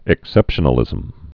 (ĭk-sĕpshə-nə-lĭzəm)